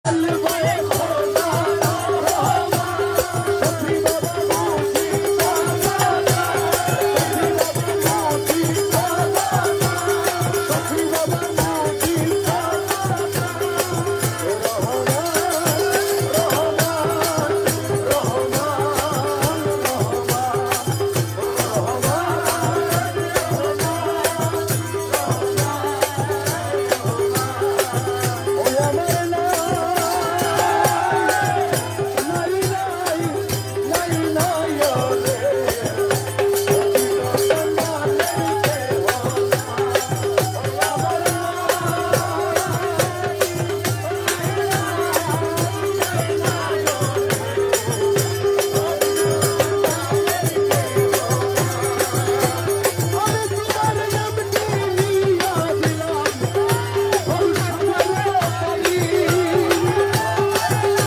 মাইজভান্ডারি সেমা মাহফিল
কাওয়ালী